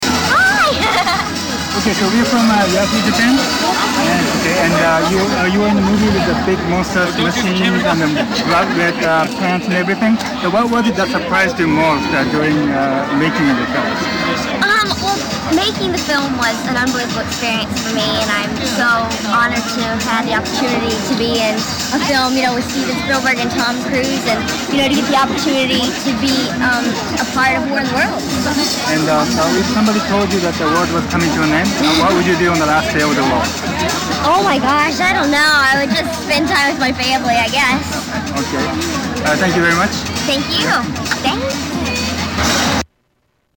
War of the World - World Premire Tokyo
Category: Celebrities   Right: Personal